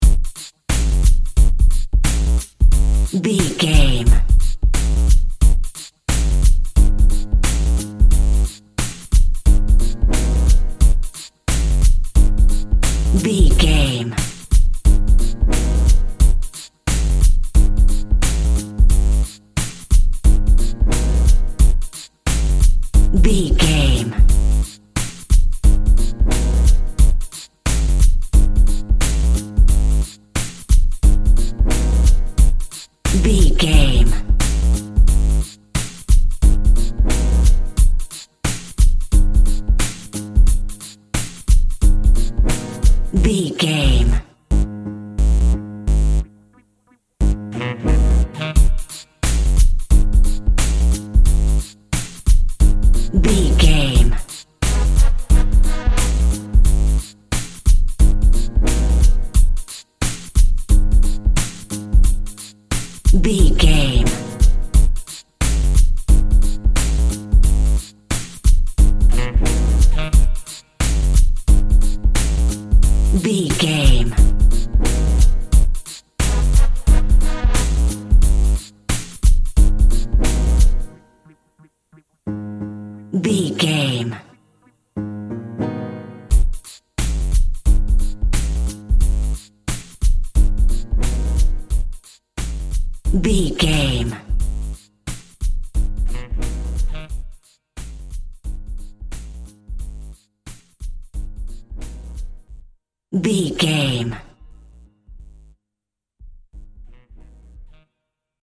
Hip Hop In London.
Aeolian/Minor
G#
synth lead
synth bass
hip hop synths
electronics